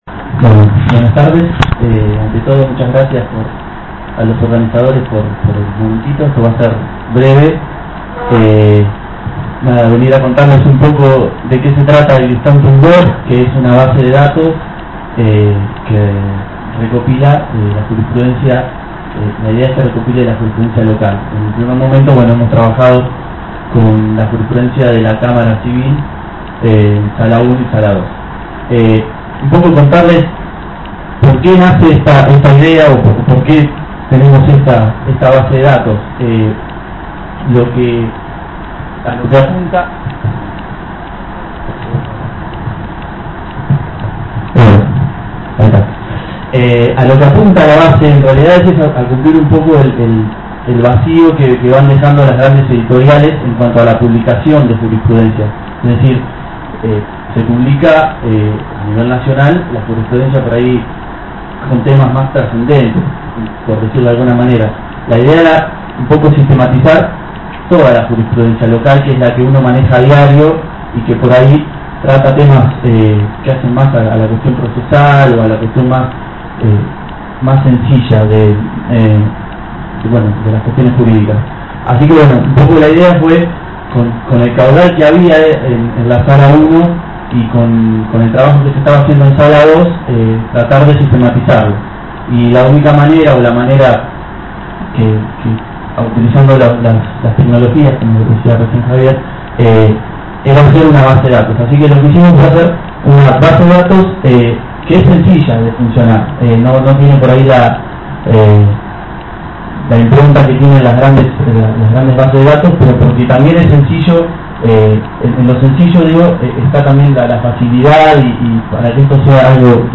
Ya están disponibles los audios de este importante evento que se realizó el 21 y 22 de octubre en Tandil.
Con un numeroso marco de público se llevaron a cabo durante el pasado viernes 21 y ayer sábado 22 de octubre en el Hotel Libertador de dicha ciudad.